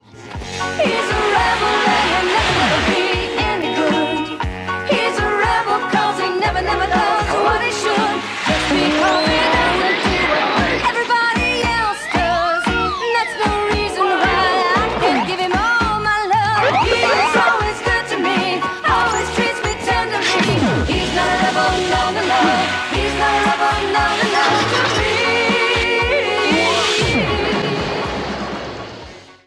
Short music sample of a cover song